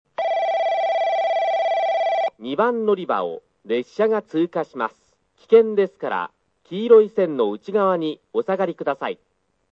スピーカー：クリアホーン
2 鹿児島本線 赤間・折尾・小倉 方面 接近放送・男性 (48KB/09秒)
※接近・通過放送、上下線共に　実際２回鳴りました。